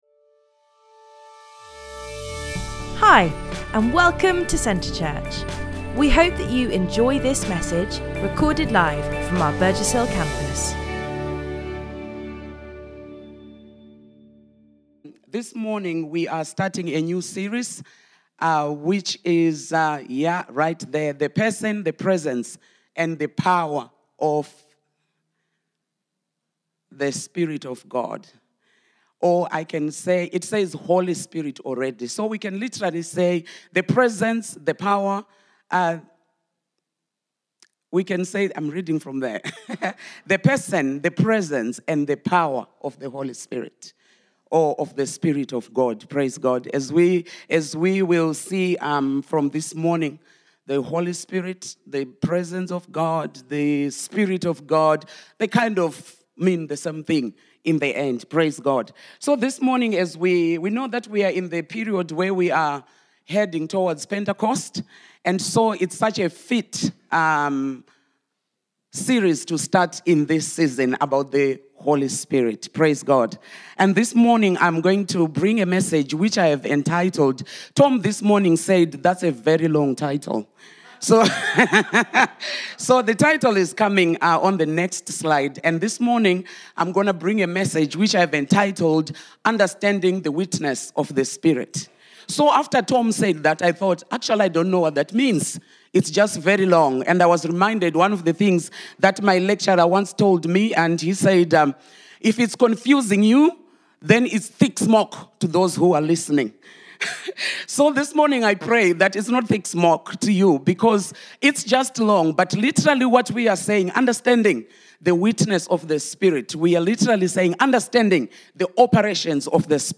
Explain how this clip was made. Join with us as we explore God together in this series of weekly podcasts recorded live at Centre Church in Burgess Hill.